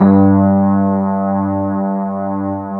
FEND1H  G1-R.wav